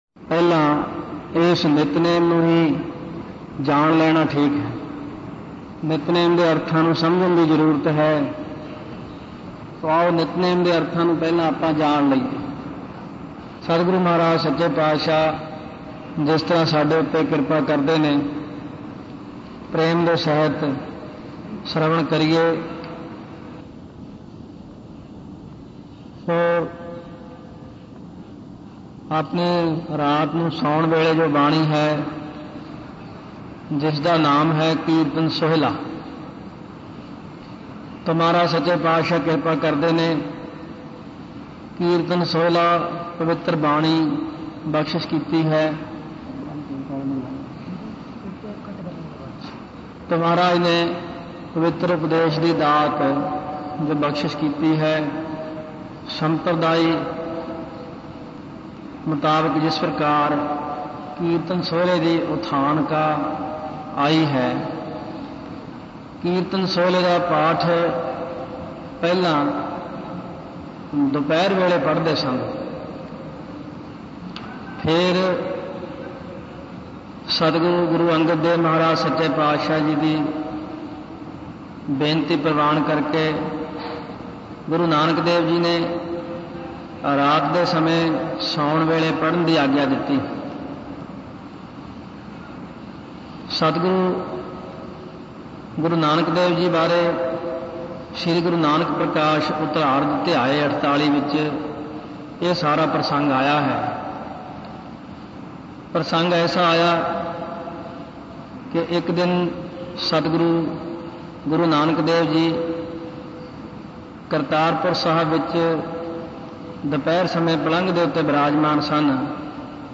Katha